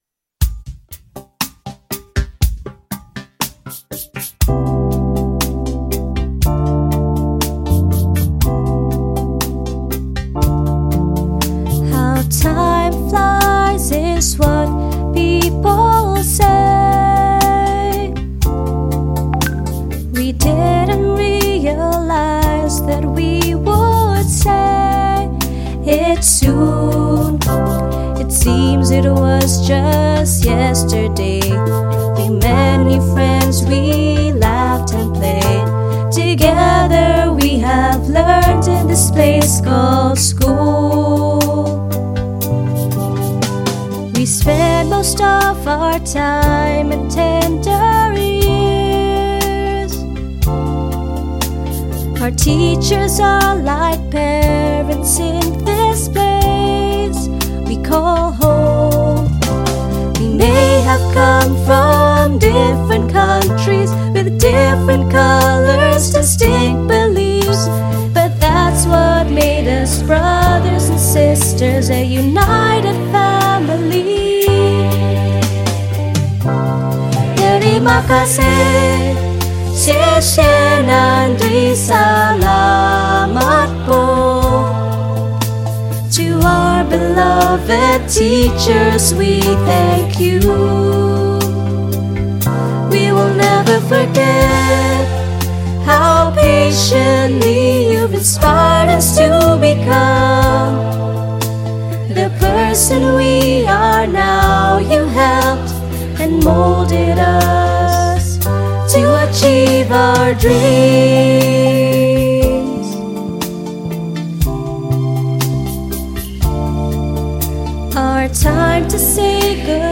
The vocals from Class of Year 2022.